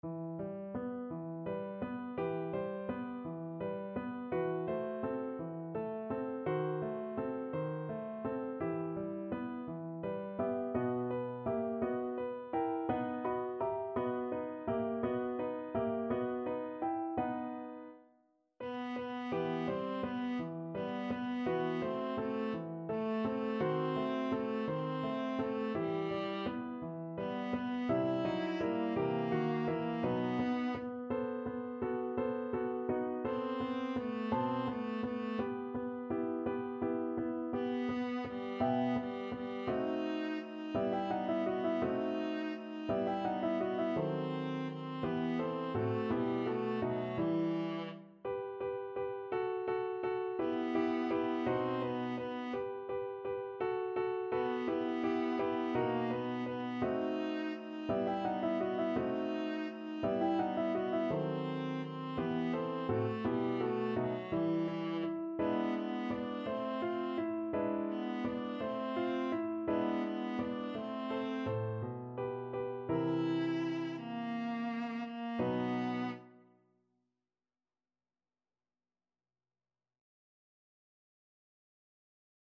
6/8 (View more 6/8 Music)
. = 56 Andante
Classical (View more Classical Viola Music)